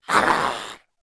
Index of /App/sound/monster/orc_magician
fall_1.wav